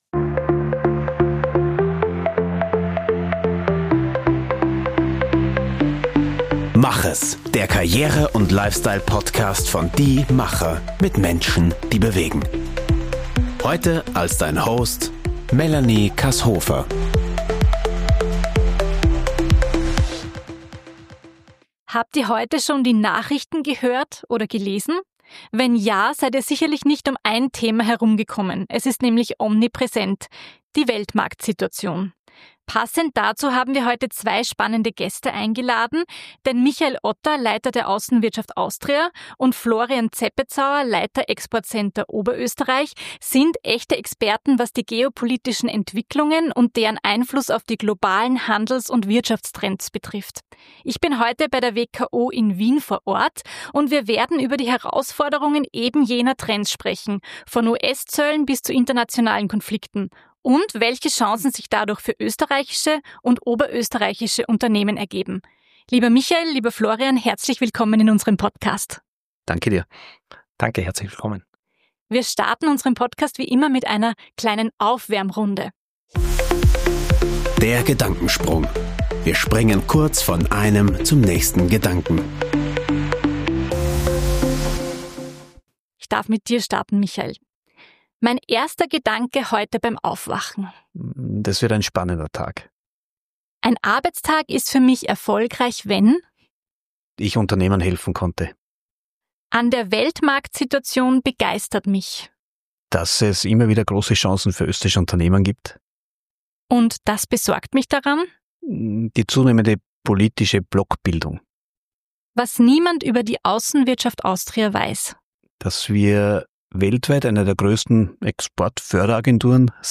Ein Gespräch mit realistischen Einschätzungen zur Weltmarktsituation, das Unternehmen Mut machen möchte, die sich bietenden Chancen aktiv zu nutzen.